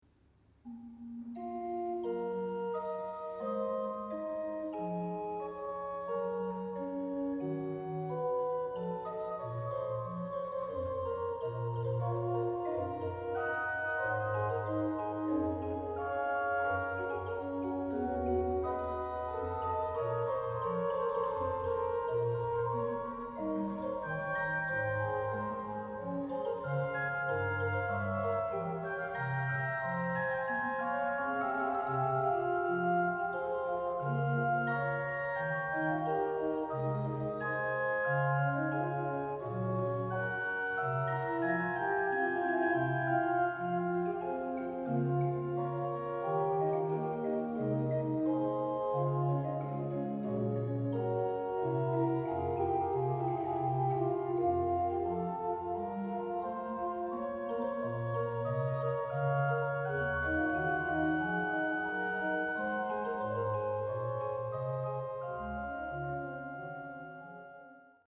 What better instrument to play it on other than the splendid new Bach organ in Thomaskiche Leipzig.
Registrations are clear, incisive and perfectly balanced, full of colour and show off the wonderful voicing of the instrument.